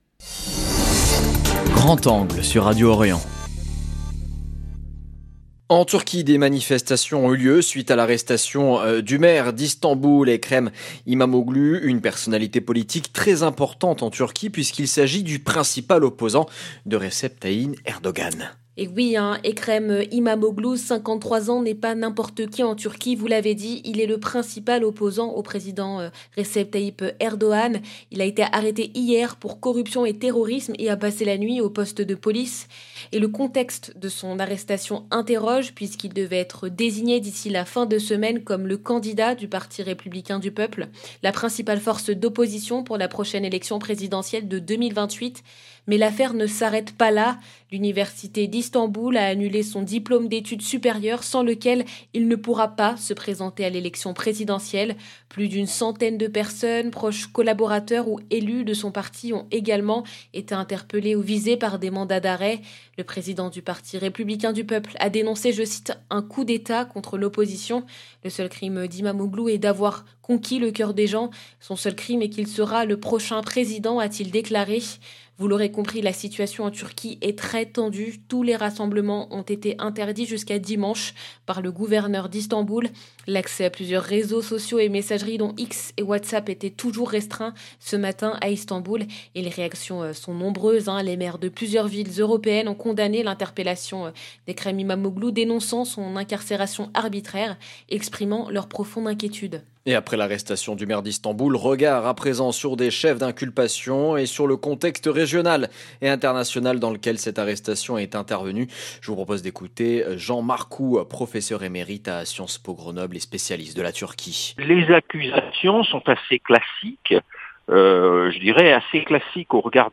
Des manifestations massives contre l’arrestation du maire d’Istanbul entretien